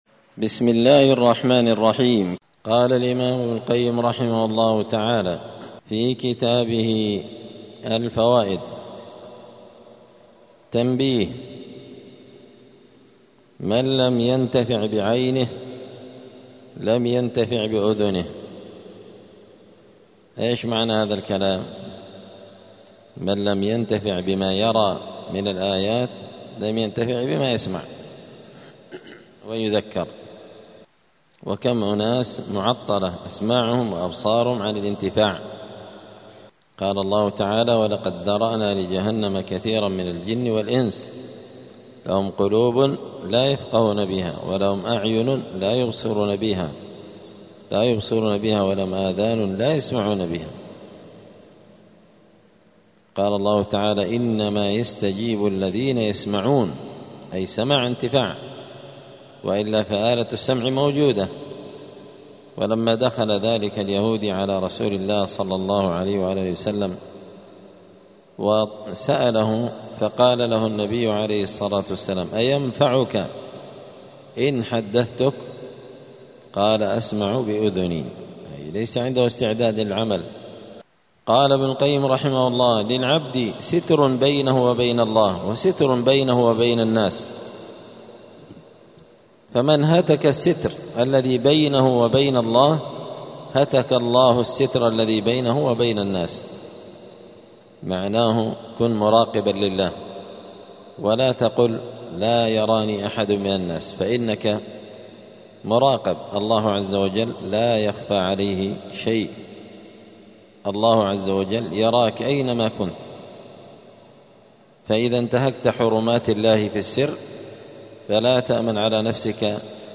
*الدرس الثالث عشر (13) (فصل: ضياع الوقت أشد من الموت)*